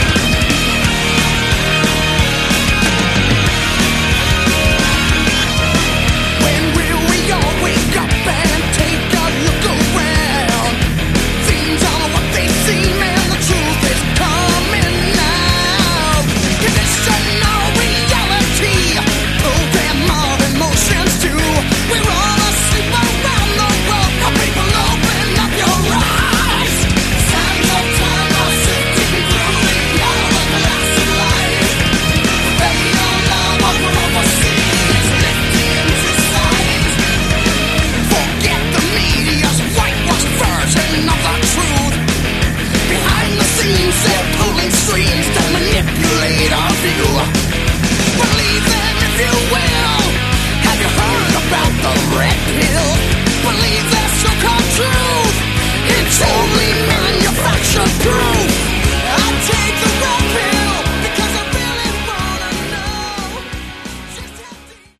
Category: Melodic Power Metal
vocals
guitars
keyboards
bass
drums